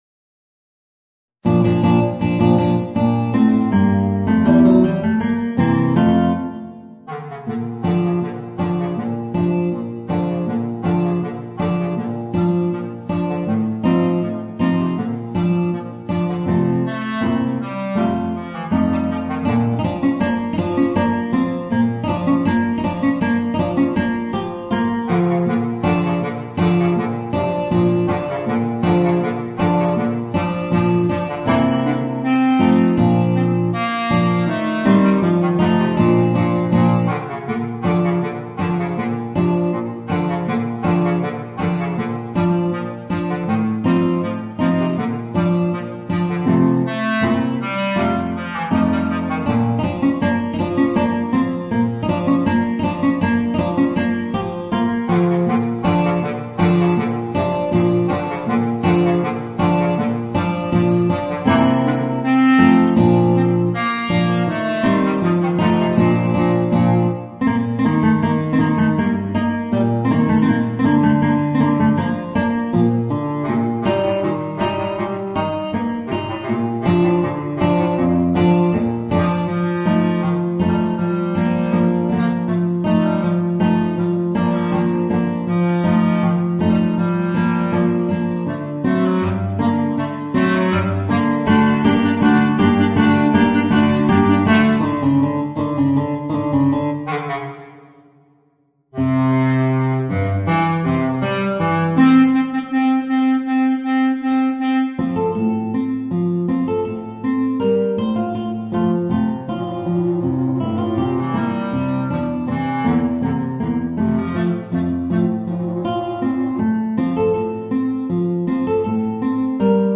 Voicing: Bass Clarinet and Guitar